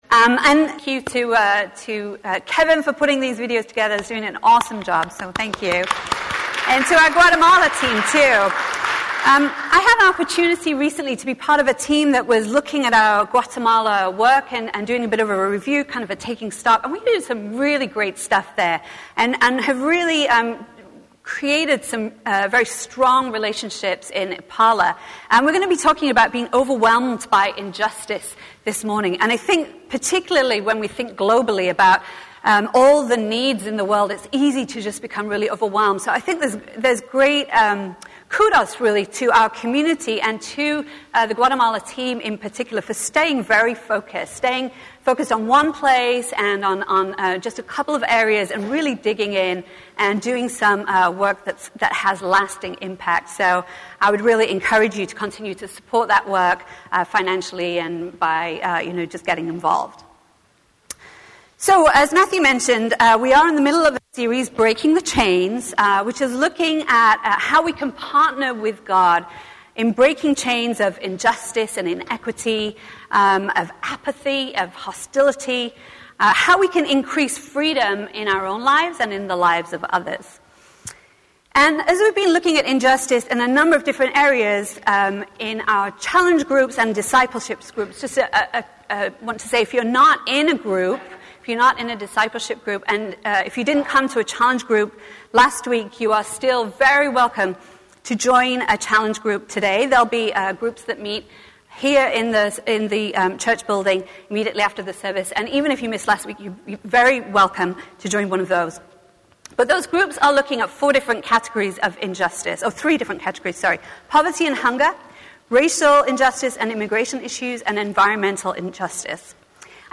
A message from the series "Winter Kindness." This week we look at our motivations for being kind, and examples from Jesus found in the gospels.